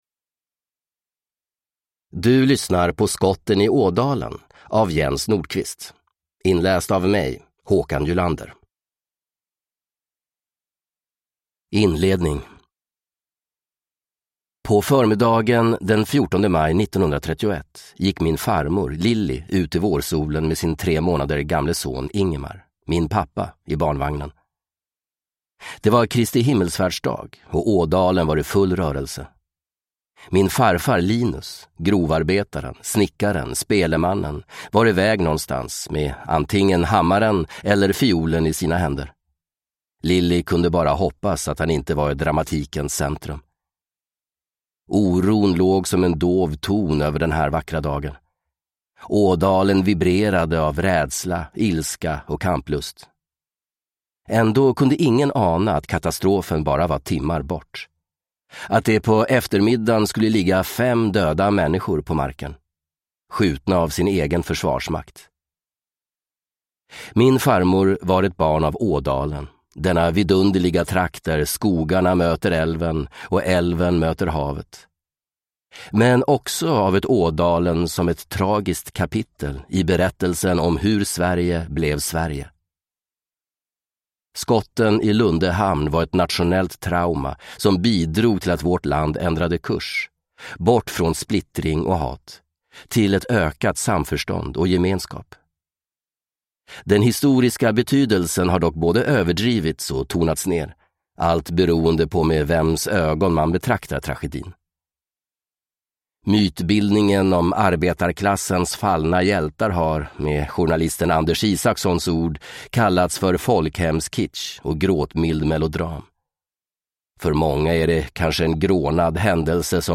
Skotten i Ådalen – Ljudbok